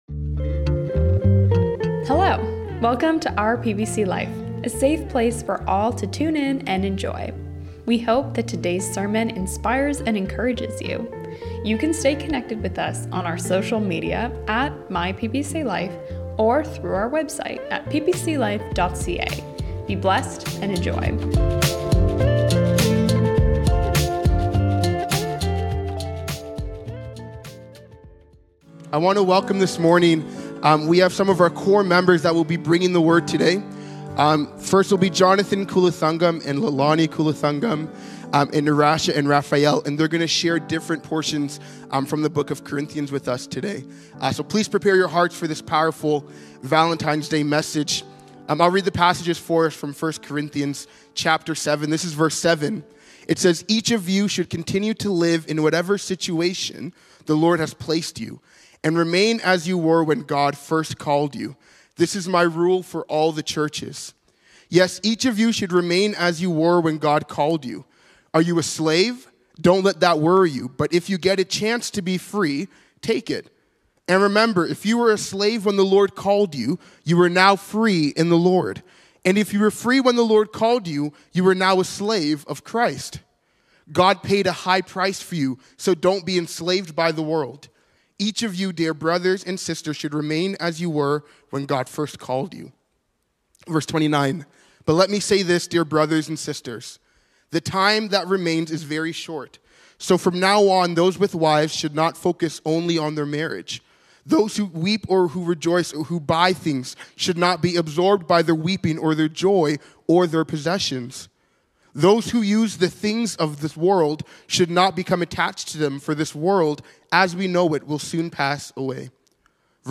Rules For Christian Relationships (Couples Sermon)